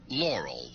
No noise. Silence.